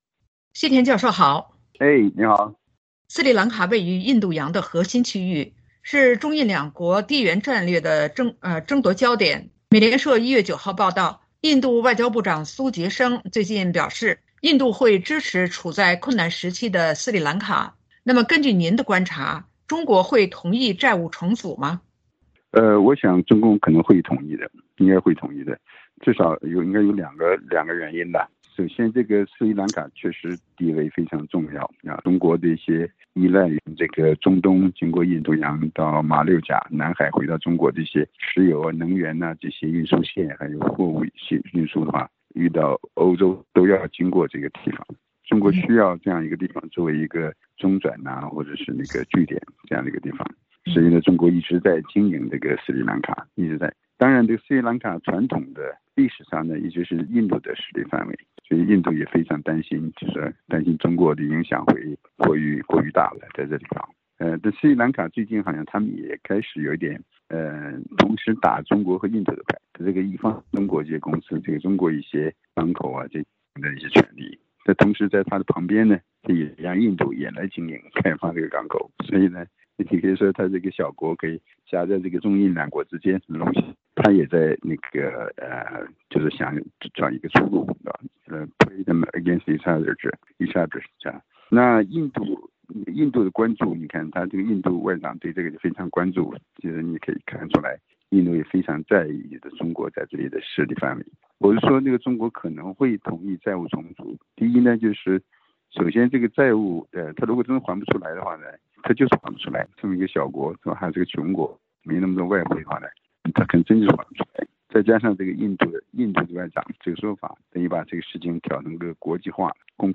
VOA连线：“一带一路” 斯里兰卡是否再次掉入中国债务“陷阱”？